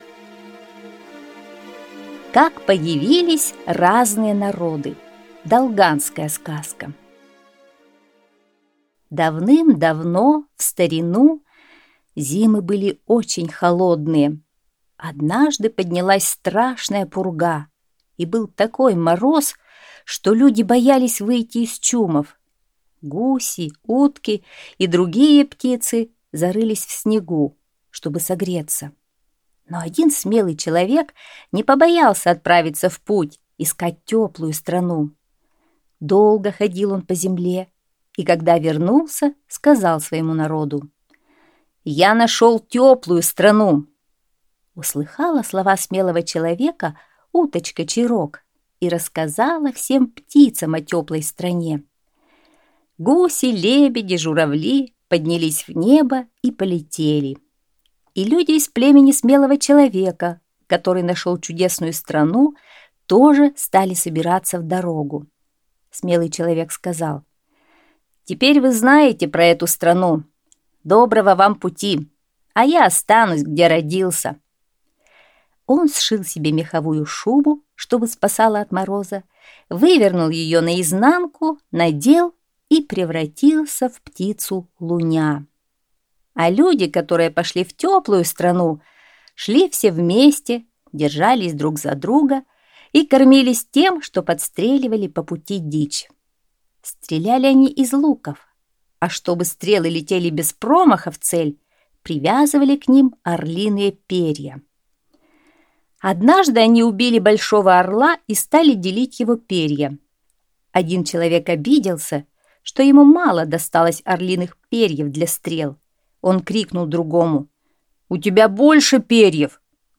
Долганская аудиосказка